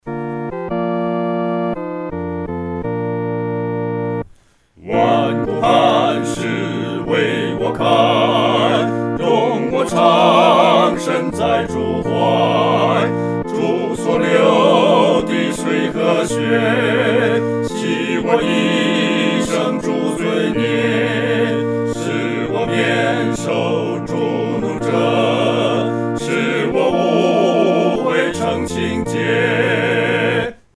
合唱（四声部）
万古磐石-合唱（四声部）.mp3